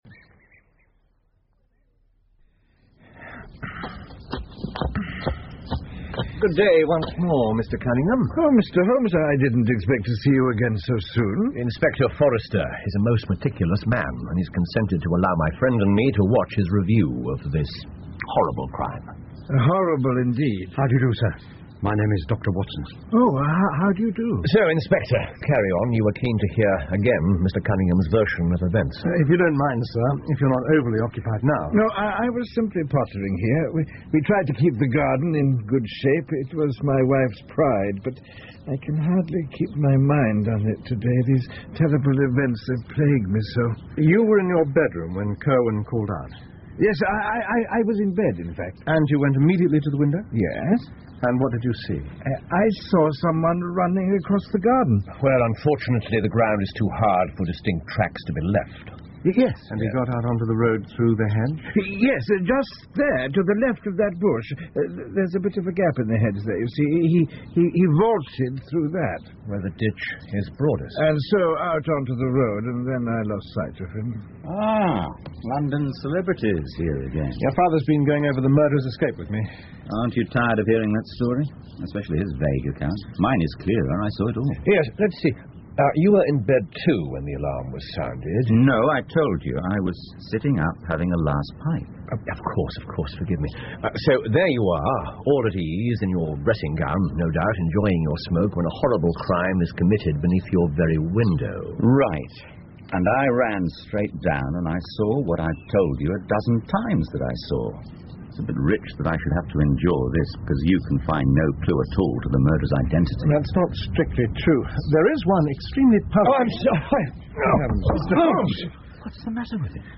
福尔摩斯广播剧 The Reigate Squires 6 听力文件下载—在线英语听力室